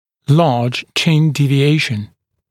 [lɑːʤ ʧɪn ˌdiːvɪ’eɪʃn][ла:дж чин ˌди:ви’эйшн]значительное смещение подбородка